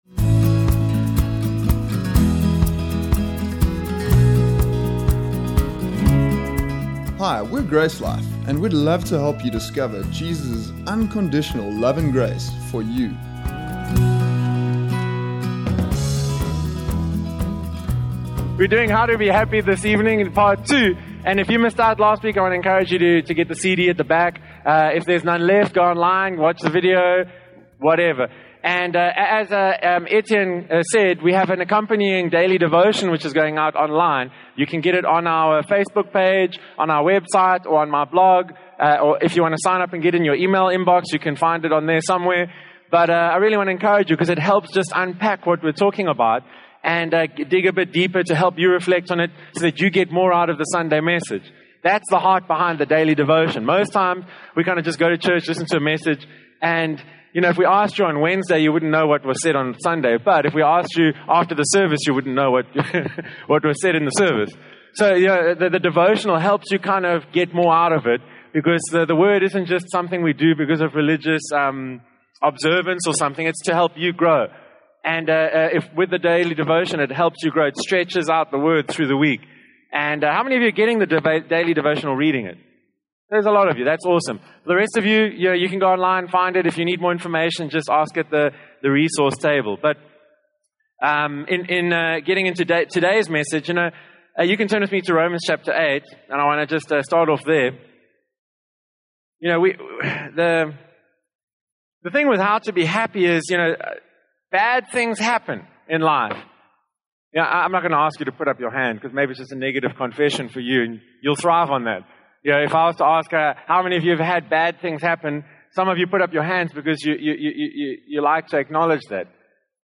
practical teaching series